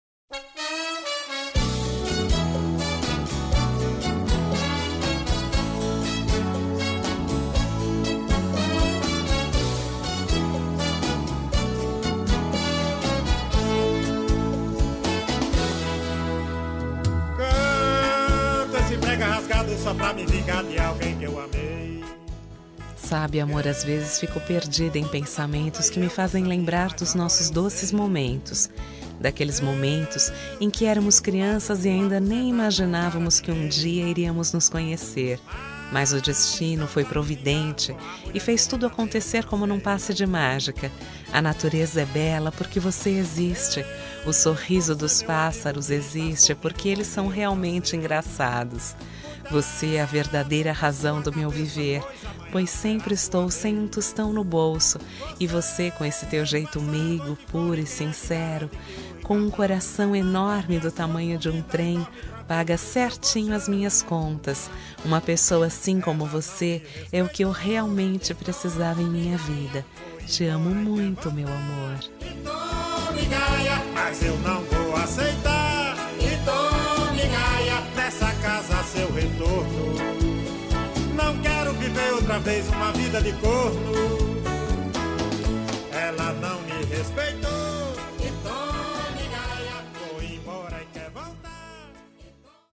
Aniversário de Humor – Voz Feminina – Cód: 200114